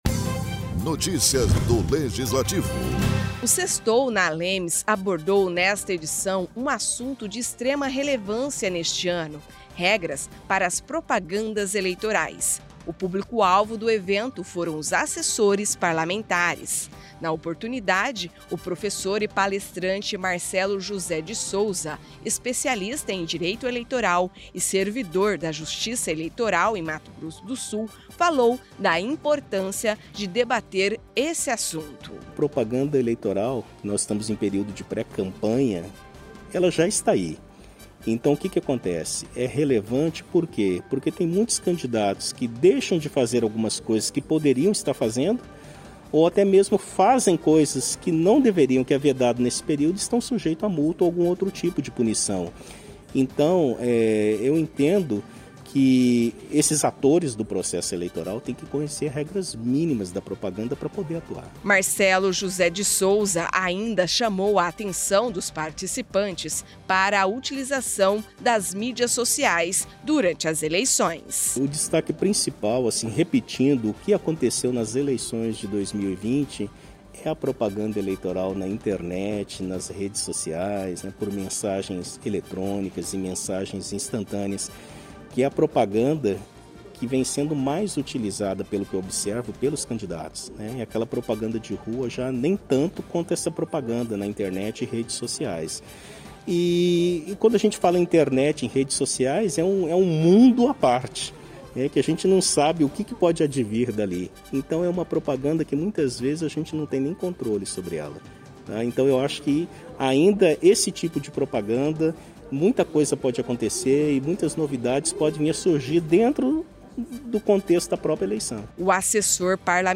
O Sextou na ALEMS abordou nesta edição um assunto de extrema relevância neste ano: Regras para as propagandas eleitorais. O público-alvo do evento foram os assessores parlamentares.